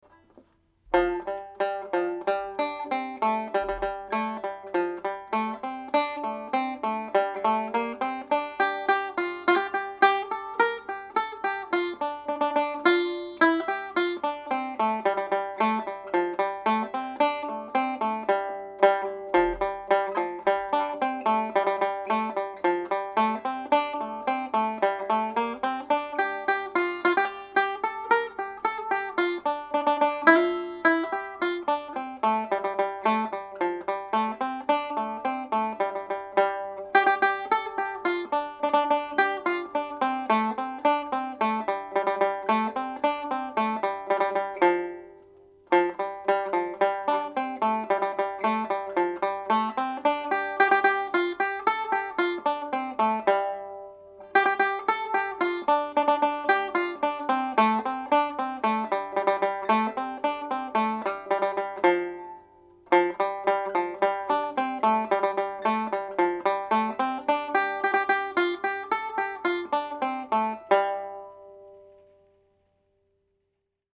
Splendid Isolation played slowly